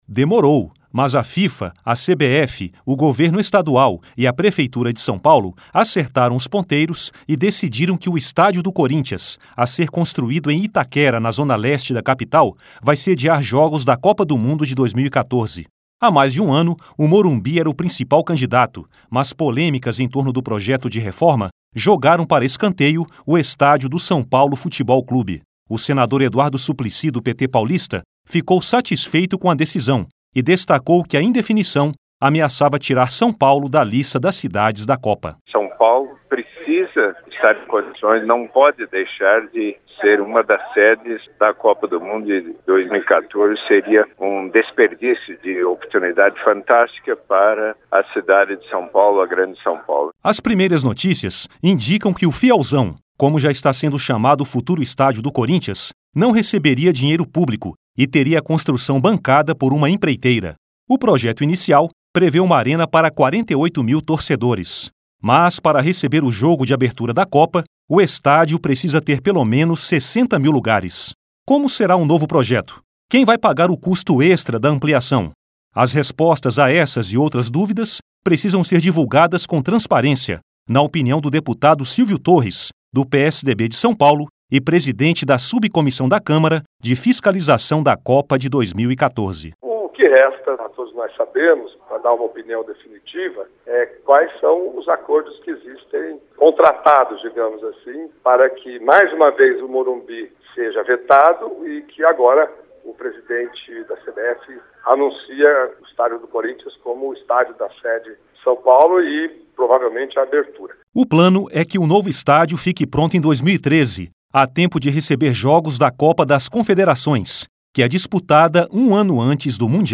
A ARENA PAULISTANA PROVAVELMENTE VAI SEDIAR O JOGO DE ABERTURA DO MUNDIAL. LOC: A DEFINIÇÃO AGRADOU O SENADOR EDUARDO SUPLICY. E O DEPUTADO SILVIO TORRES COBRA A DIVULGAÇÃO DE DETALHES DO PROJETO.